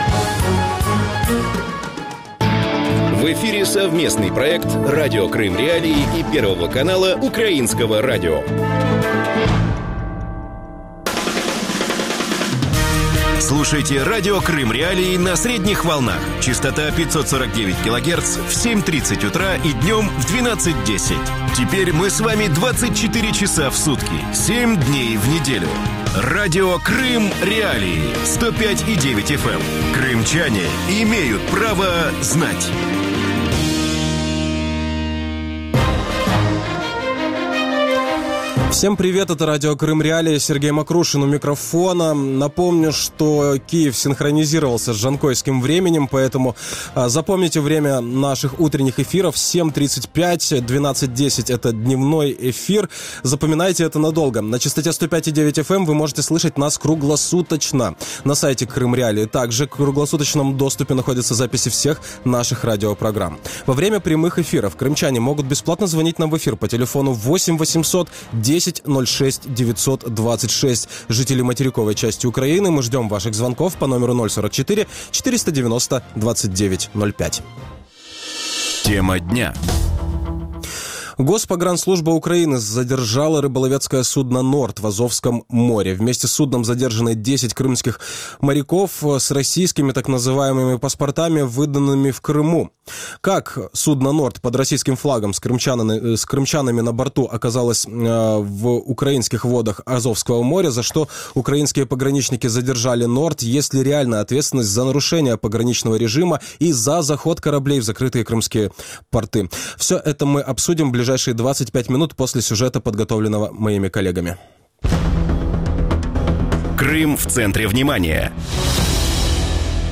Егор Ребров, заместитель начальника управления прокуратуры АРК